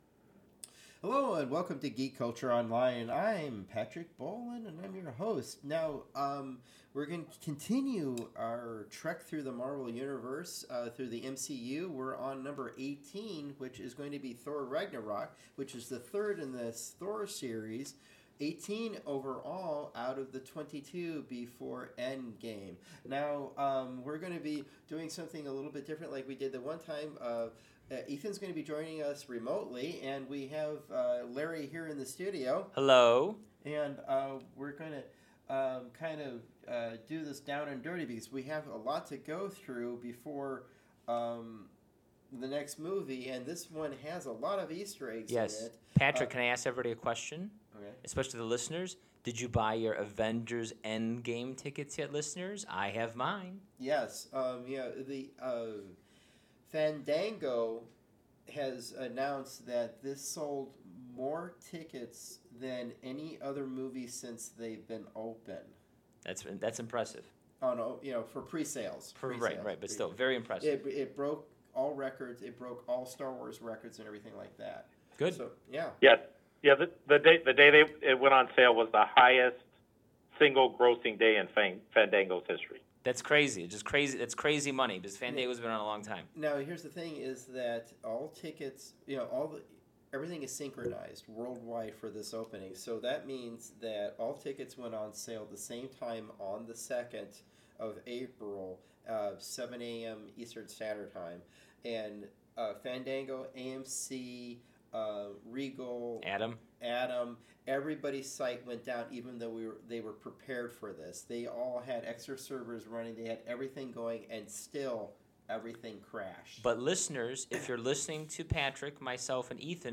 There are times when his connection broke up so please listen and take heart.